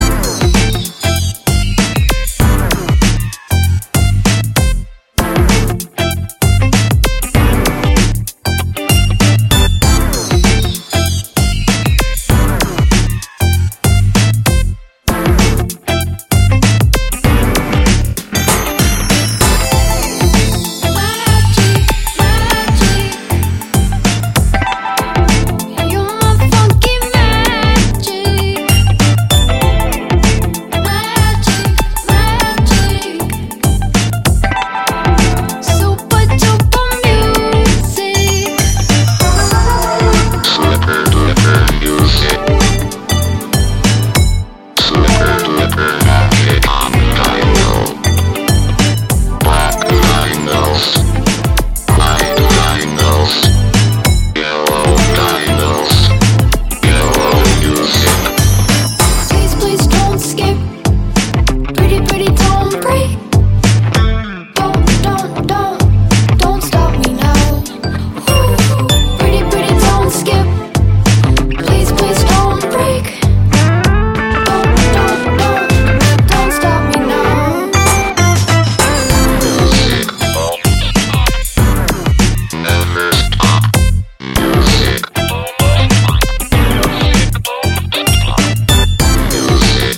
キャッチーかつポップ、室内でも屋外でも気分が上がって心踊れる極上のトラック満載のアルバムです。』